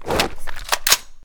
draw.ogg